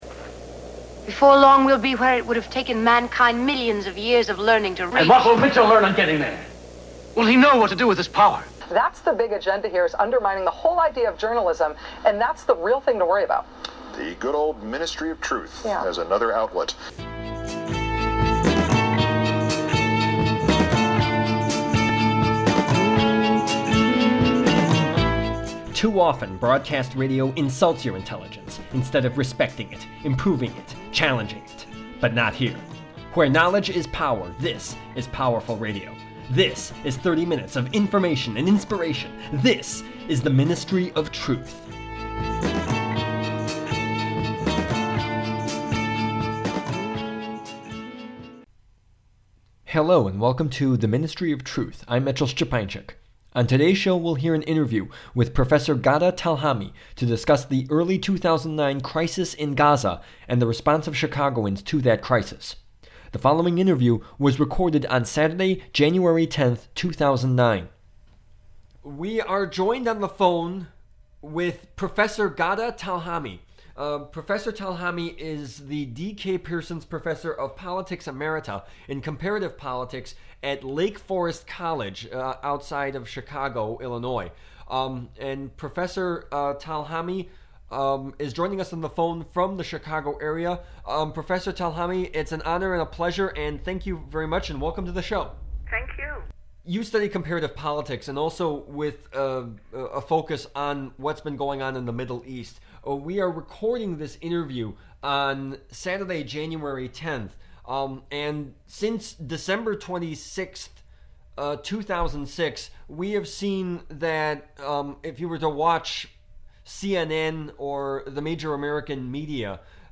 The Ministry of Truth: Interview